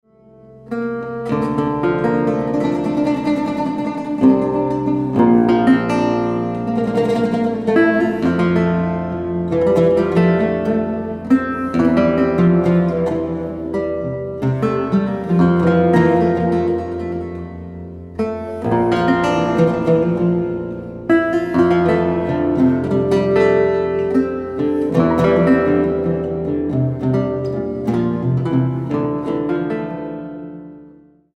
Contemporary
Lute , Oud